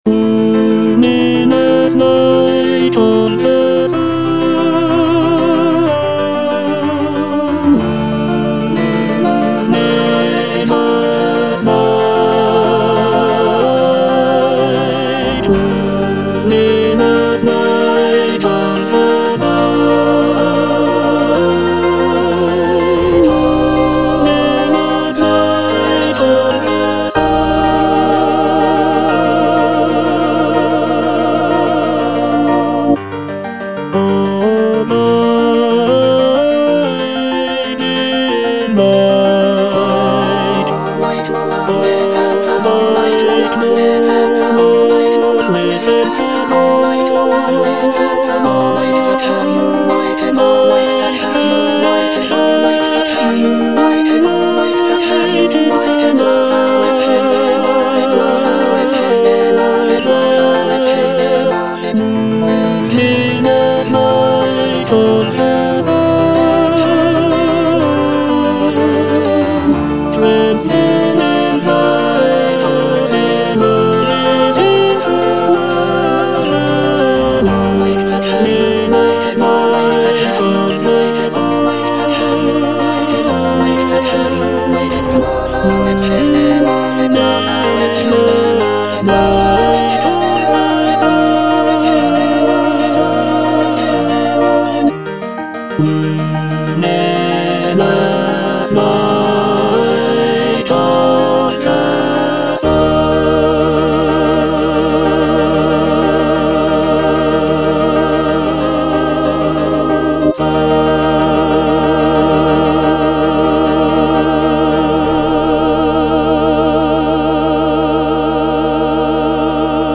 (tenor I) (Emphasised voice and other voices) Ads stop
choral work